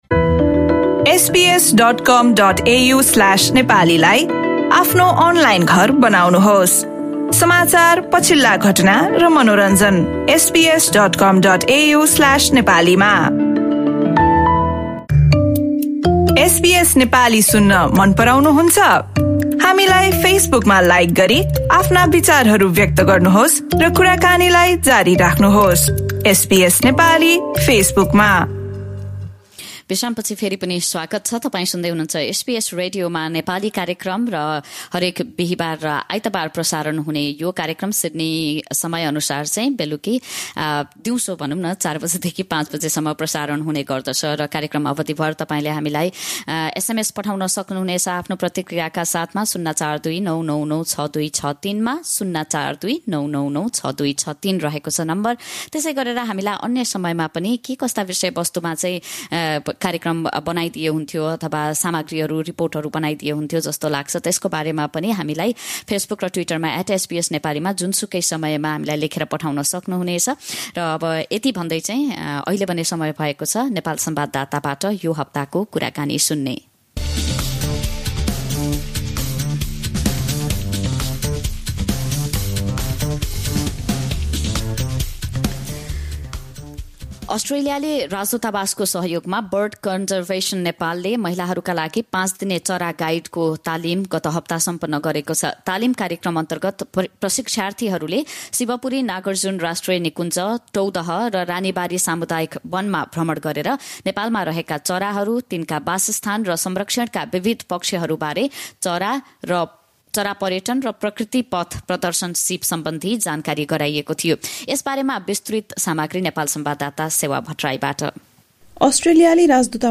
रिपोर्ट।